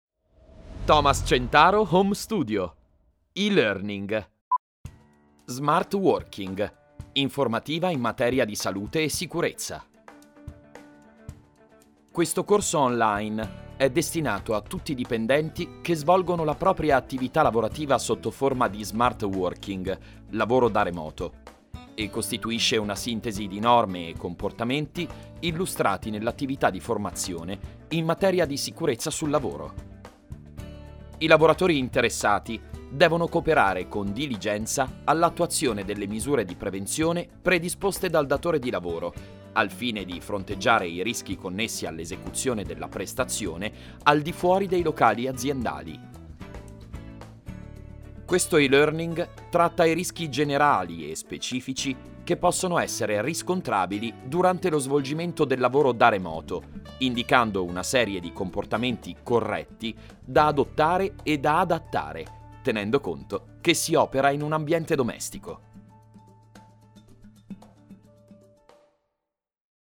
ITALIAN MALE VOICE OVER ARTIST
I own the latest technology equipment wrapped in a soundproof environment for high quality professional recordings that guarantee a result in line with the prestige of the brand to be promoted.
E-LEARNING